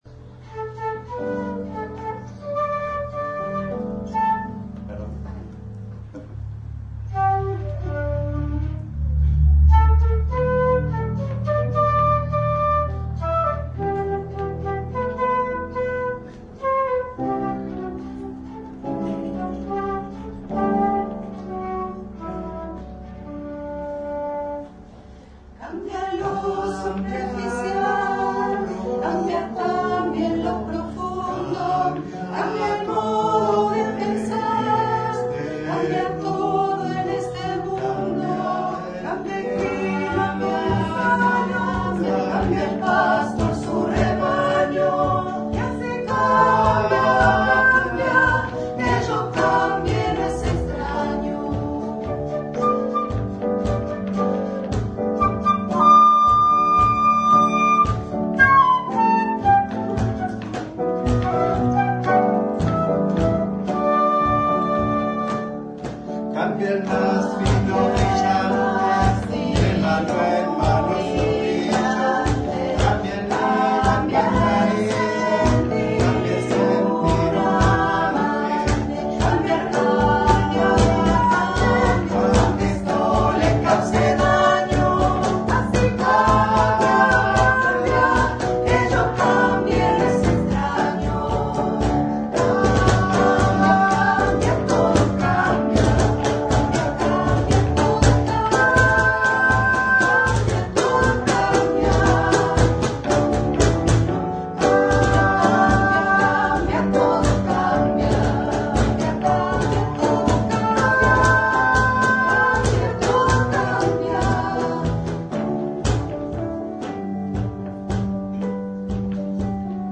11-coro Todo Cambia
Se presentó el Coro y la Orquesta Municipal